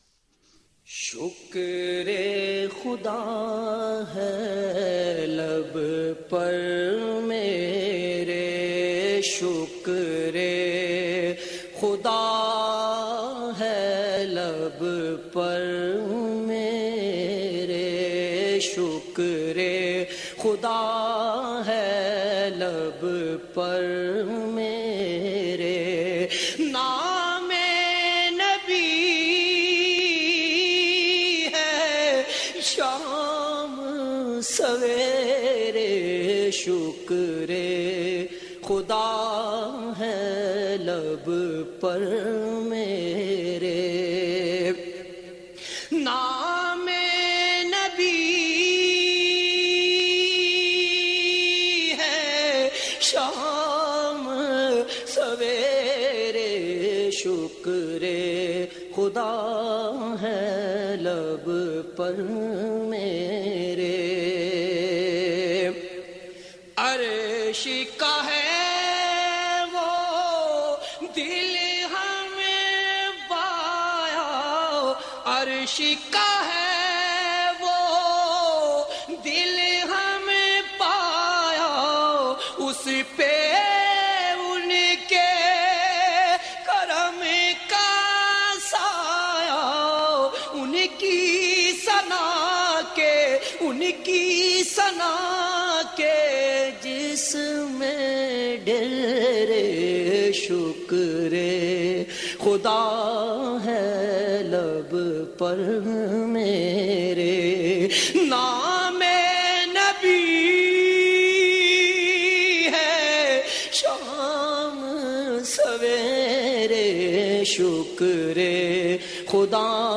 نعت رسول مقبول (ص)